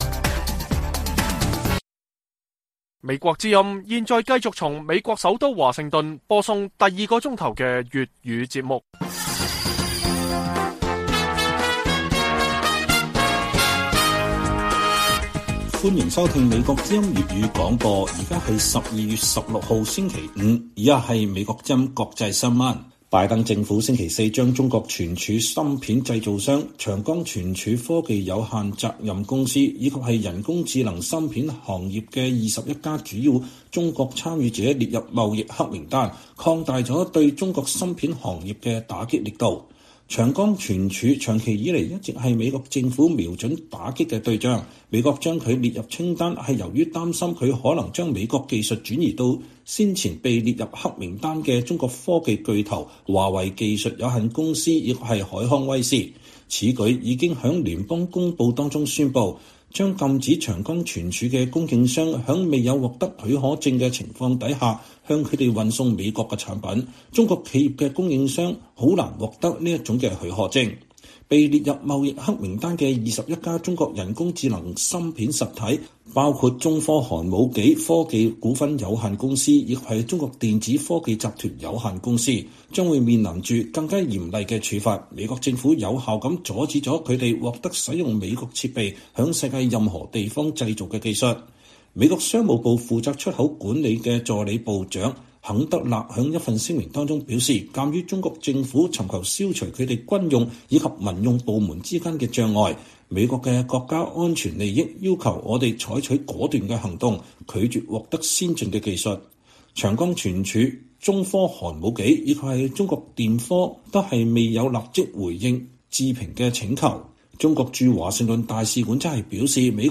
粵語新聞 晚上10-11點: 拜登政府將“長江存儲”等企業列入黑名單